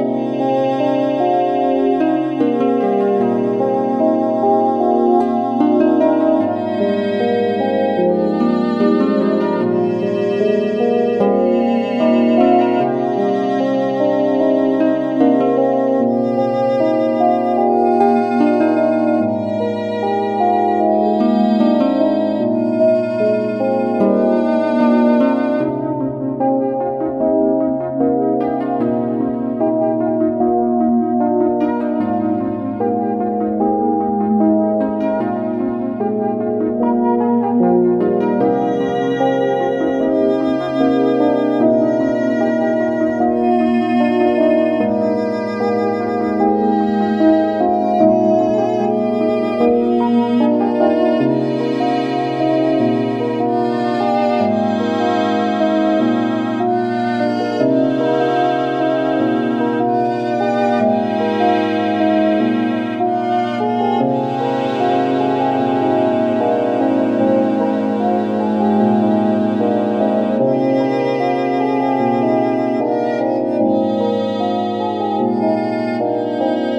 A small space - ish fantasy music.